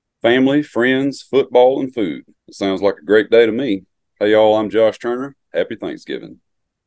LINER Josh Turner - Thanksgiving